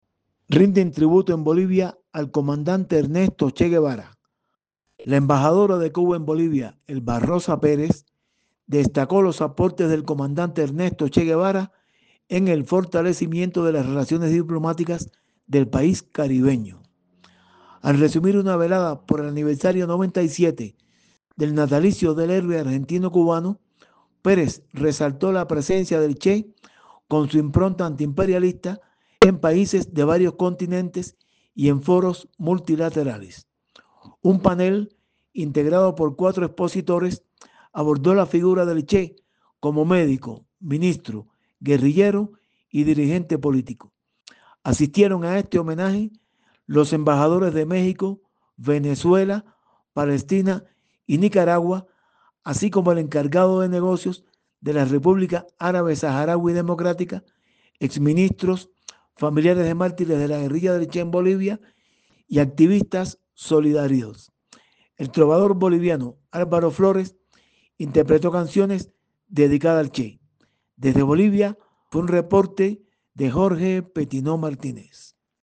Desde Bolivia, fue un reporte